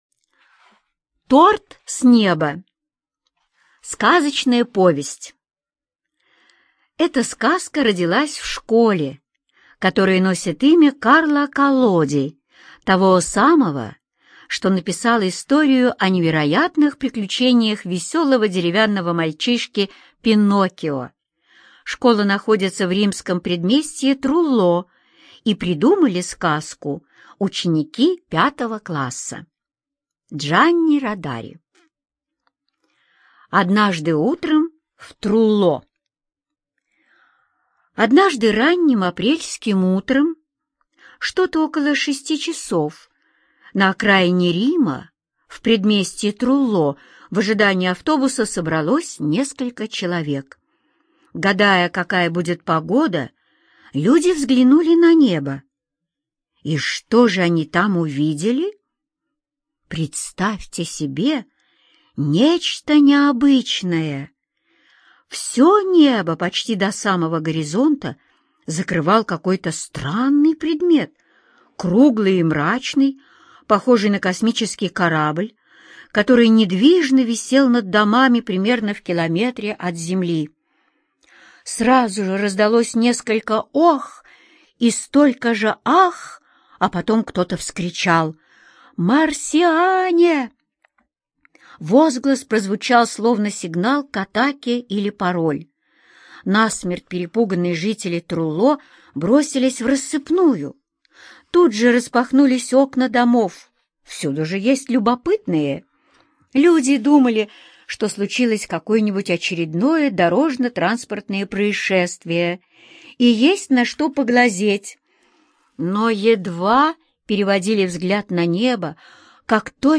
Торт с неба - аудиосказку Родари Д. Однажды жители городка увидели на небе большой круглый предмет и подумали, что прилетели марсиане.